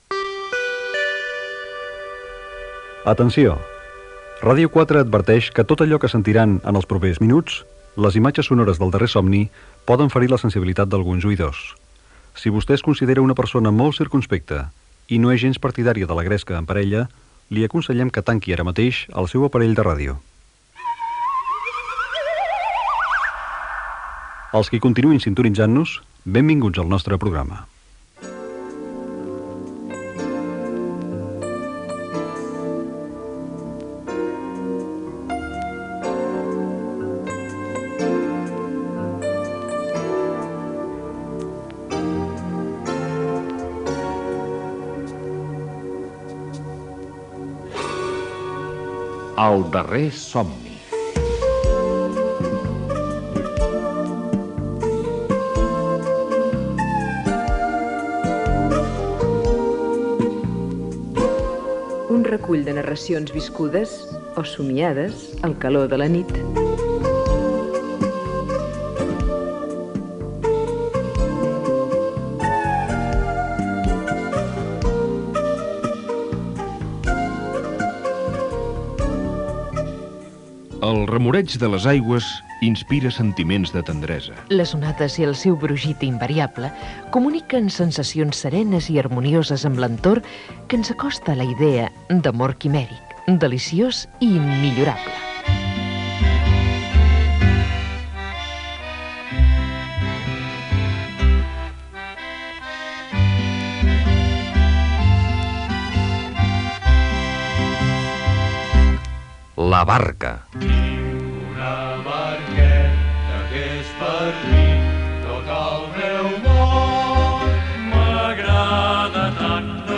Gènere radiofònic Ficció
Banda FM